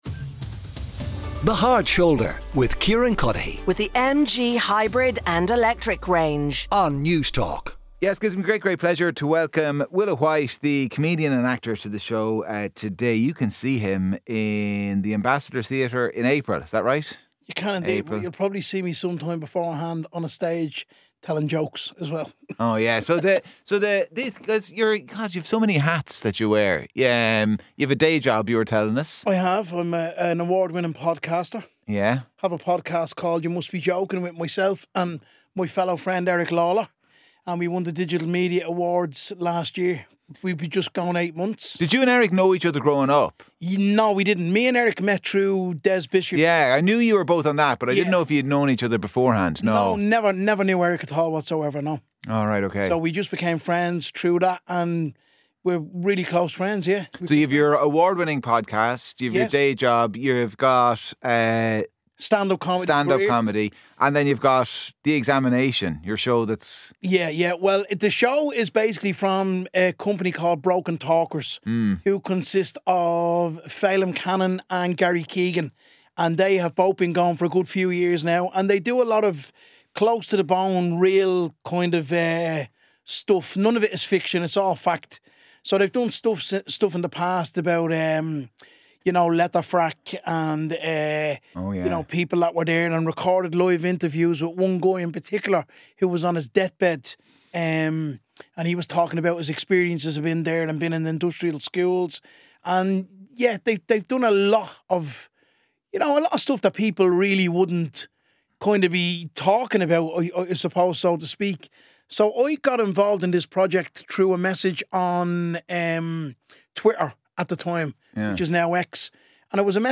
Play Rate Apps Listened List Bookmark Share Get this podcast via API From The Podcast The Thursday Interview on The Hard Shoulder The Hard Shoulder sits down with public figures from the world of sport, politics, entertainment and business for wide-ranging chats about their life stories.